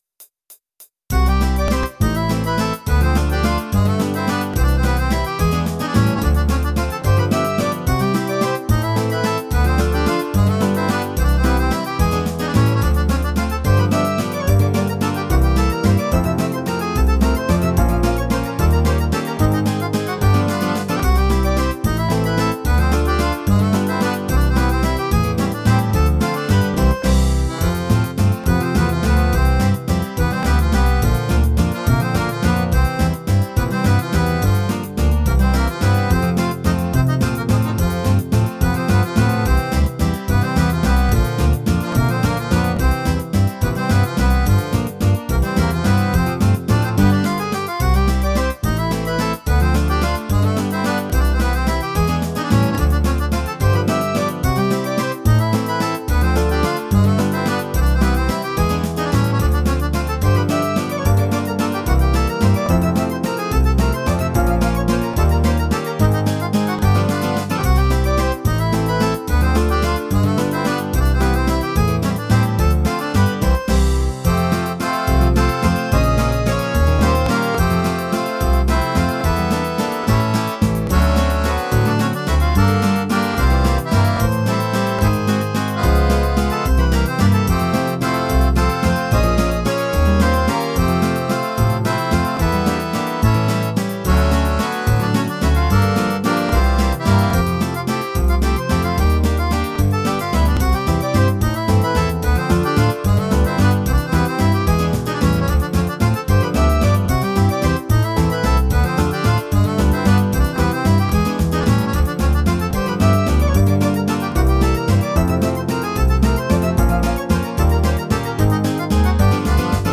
multi-track instrumental version